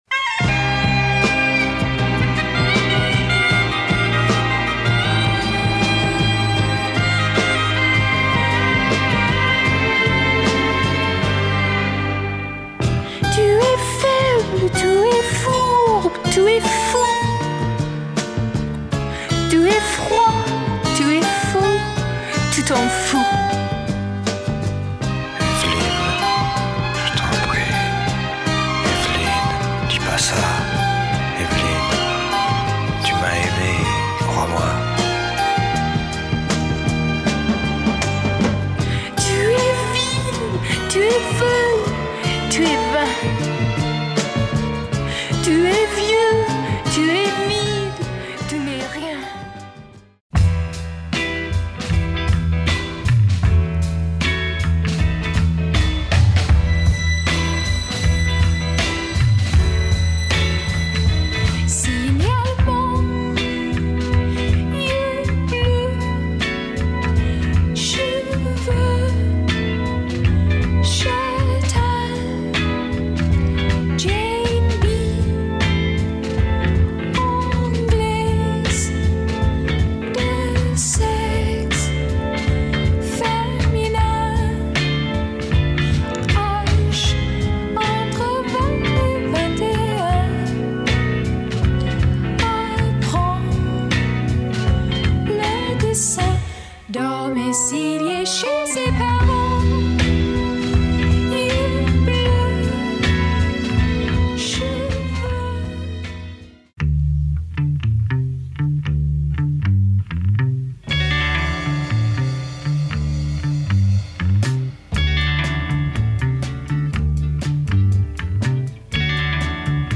Recorded in London